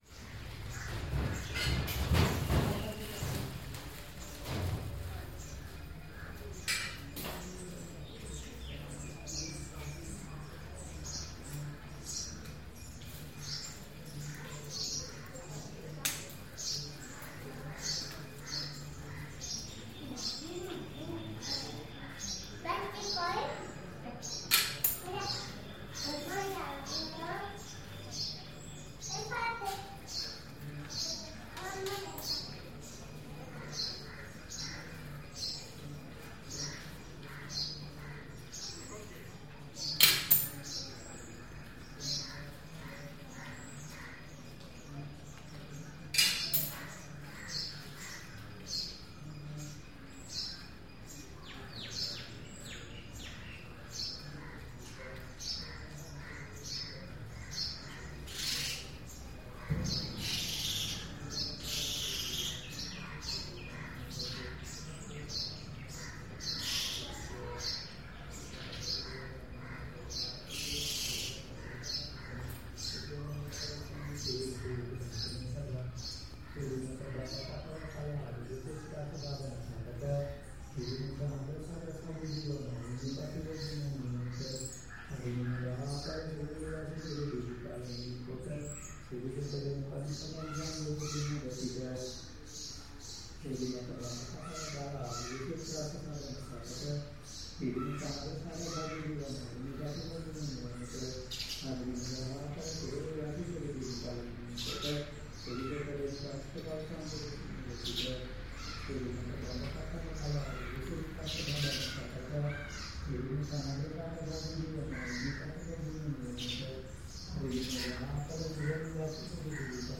Tooth Temple - ambience, and elephant bath